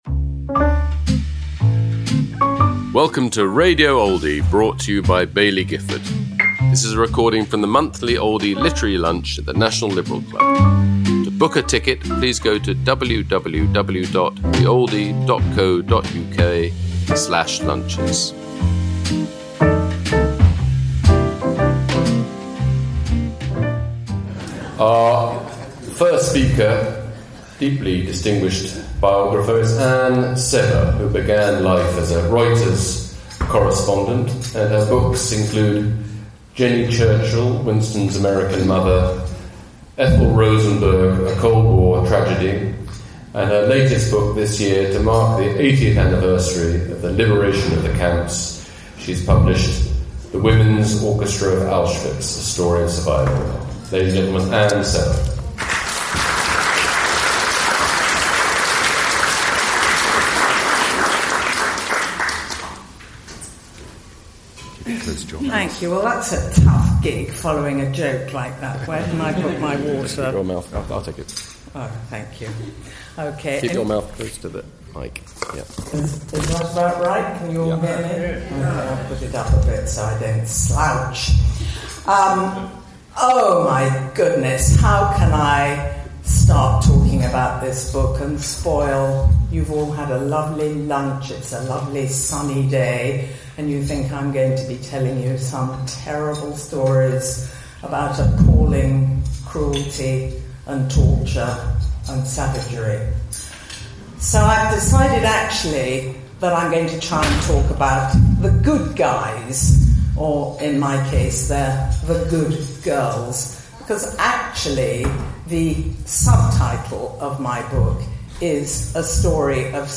Anne Sebba speaking about her new book, The Women's Orchestra of Auschwitz: A Story of Survival, at the Oldie Literary Lunch, held at London’s National Liberal Club, on June 24th 2025.